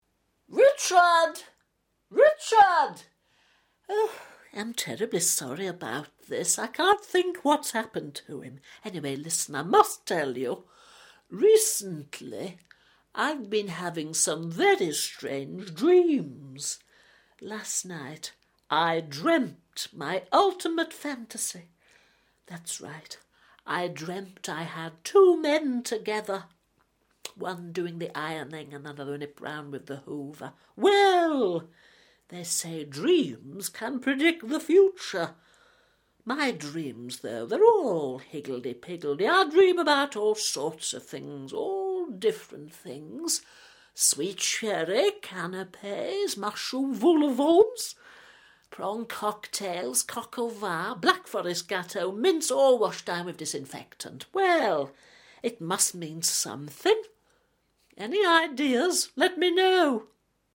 Steve Nallon as Hyacinth Bucket